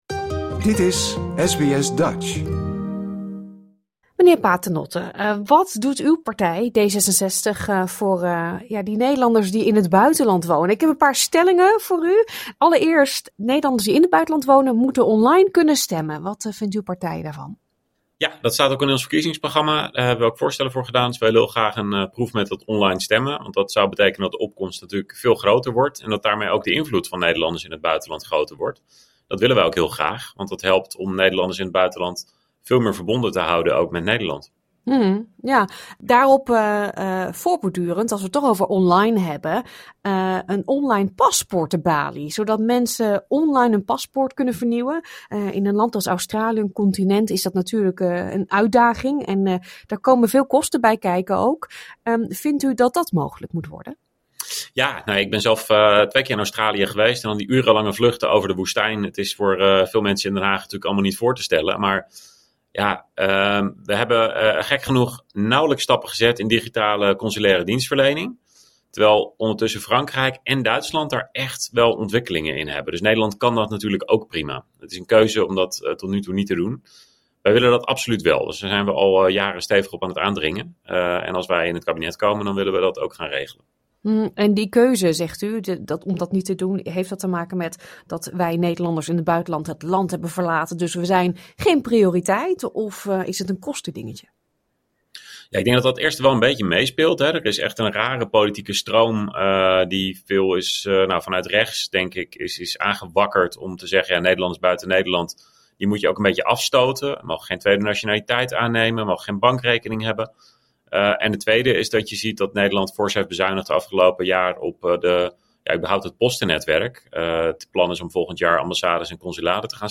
SBS Dutch sprak met vier partijen* en legde iedere kandidaat dezelfde stellingen en vragen voor.